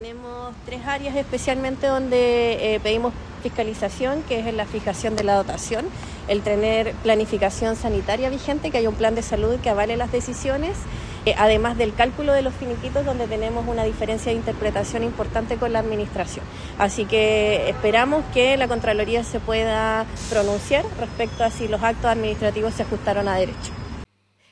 En ese contexto, la concejala de San Pedro de la Paz, Camila Ortiz, explicó los principales puntos que se buscan aclarar con esta fiscalización.